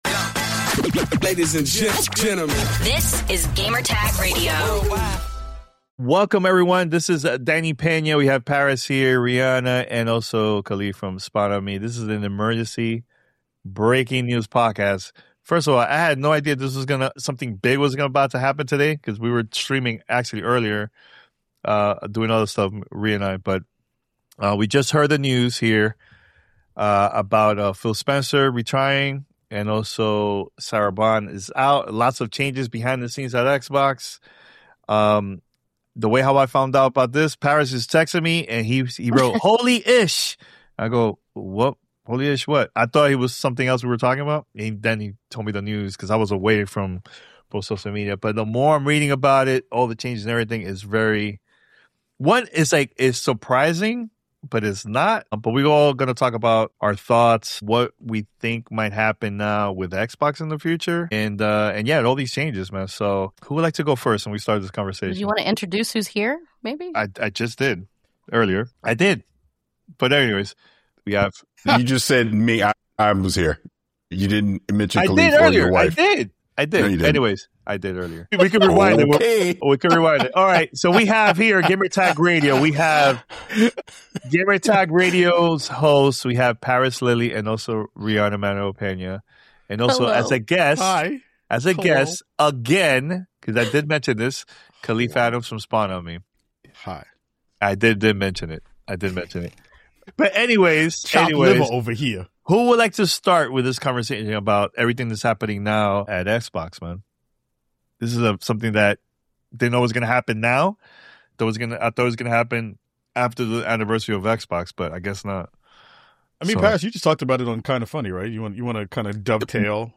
Phil Spencer’s retirement and Sarah Bond’s surprise resignation have everyone asking: what the heck just happened behind closed doors at Xbox? Check out our roundtable discussion about all the changes happening at Xbox.